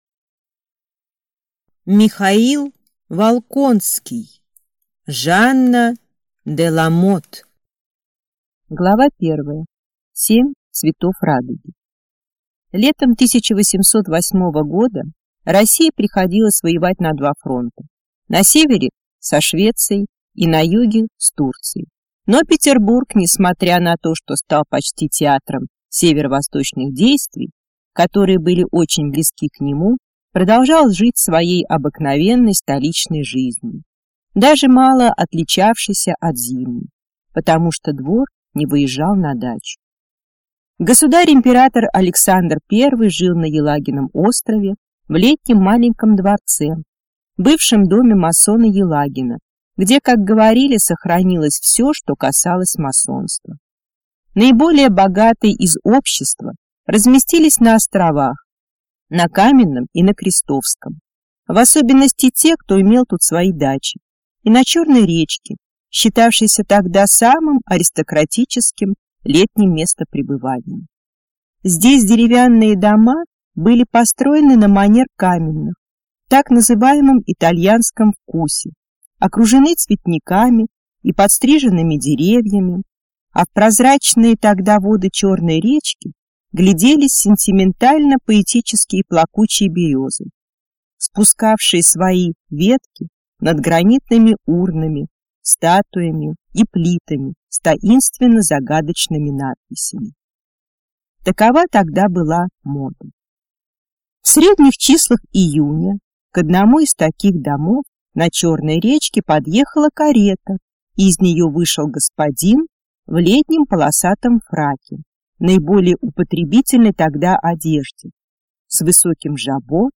Аудиокнига Жанна де Ламот | Библиотека аудиокниг